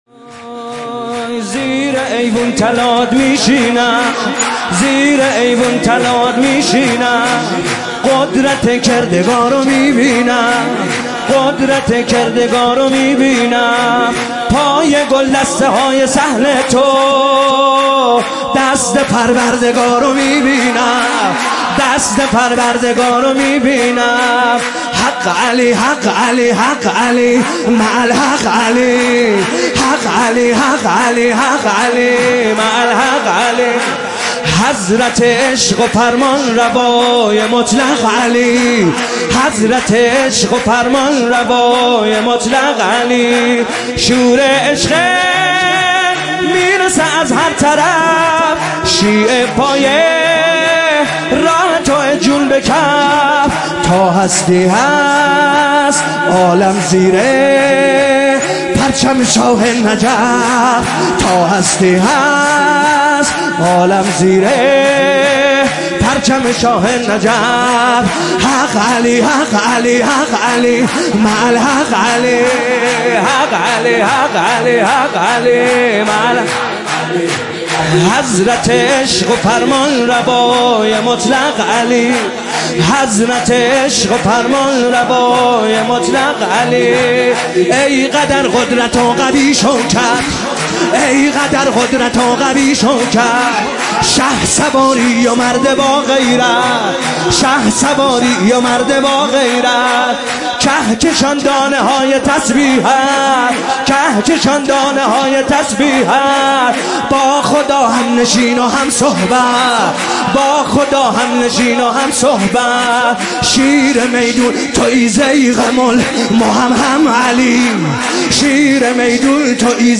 مولودی شاد عید غدیر
سرود عید غدیر
شب عید غدیر